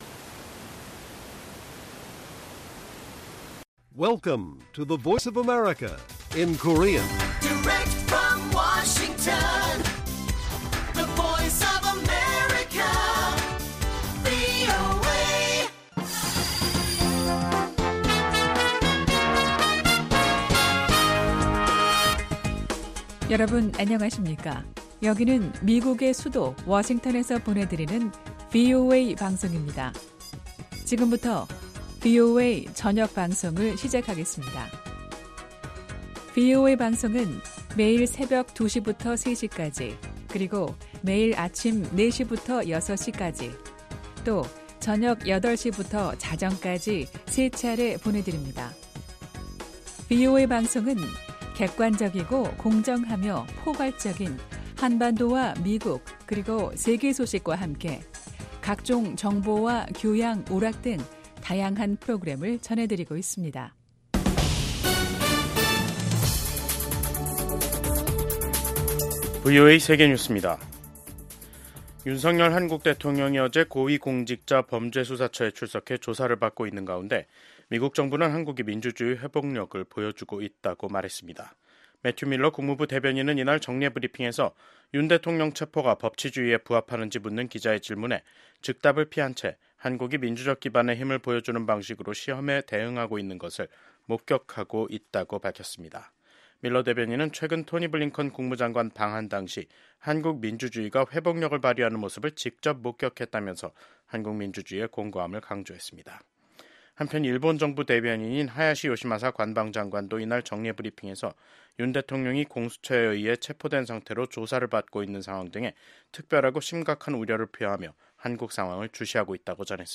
VOA 한국어 간판 뉴스 프로그램 '뉴스 투데이', 2025년 1월 16일 1부 방송입니다. 조 바이든 미 행정부와 윤석열 한국 정부가 핵협의그룹(NCG) 출범 등으로 강화시킨 미한 확장억제가 도널드 트럼프 새 행정부 아래에서도 그 기조가 유지될 지 주목됩니다. 미국 국무부는 한국이 정치적 혼란 속에서 민주주의의 공고함과 회복력을 보여주고 있다고 말했습니다.